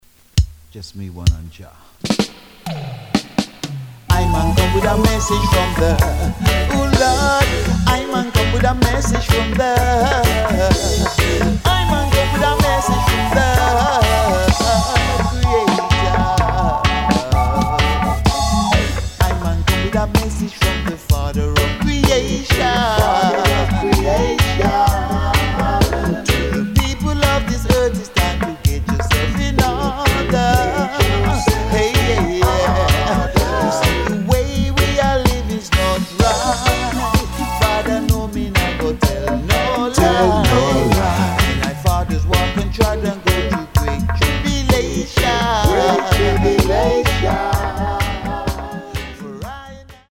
Recorded: Shanti Studio's